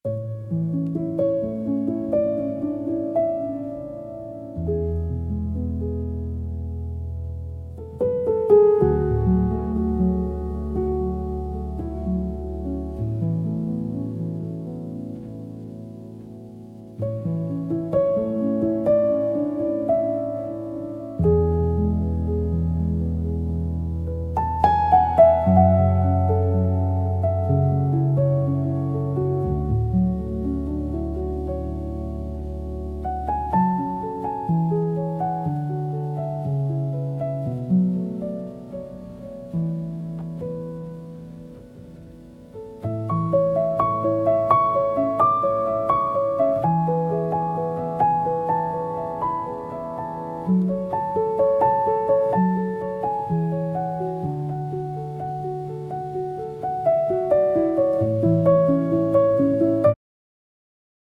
AI(Suno)が作ったピアノ曲 (1分01秒)
染み渡る感じ。